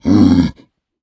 Minecraft Version Minecraft Version snapshot Latest Release | Latest Snapshot snapshot / assets / minecraft / sounds / mob / piglin / angry4.ogg Compare With Compare With Latest Release | Latest Snapshot
angry4.ogg